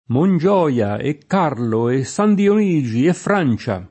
Mongioia [monJ0La] top. m. (Piem.) — cima delle Alpi Cozie — anche adattam. ant. del fr. Montjoie come grido di guerra: «Mongioia!» e «Carlo!» e «San Dionigi!» e «Francia!» [